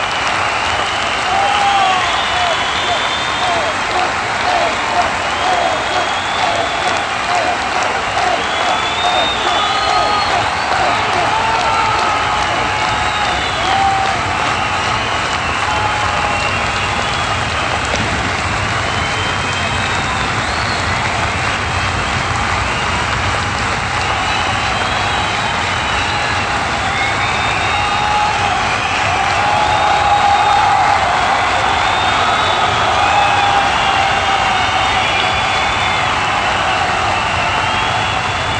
Each sound Fx file has been digitally remastered from their original sources for optimum sound level and reproduction on the PFx Brick.
Stadium Crowd 1
StadiumCrowd1.wav